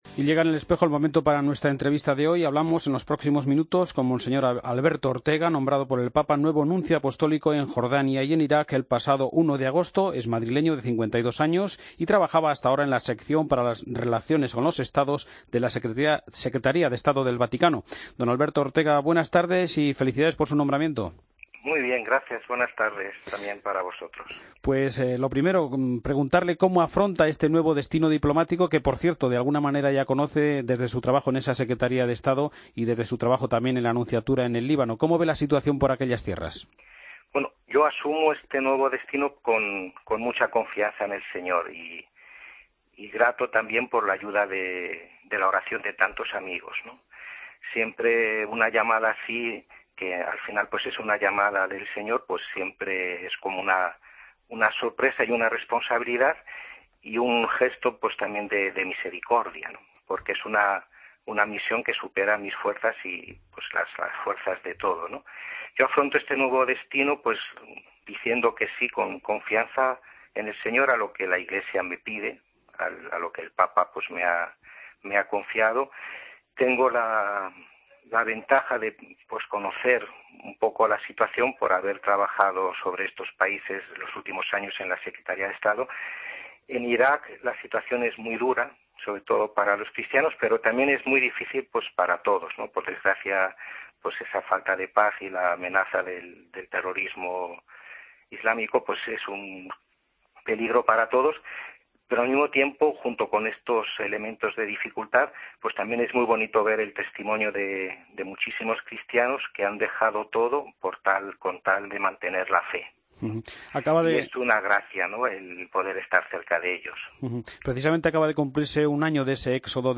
Escucha la entrevista a Monseñor Alberto Ortega en el Espejo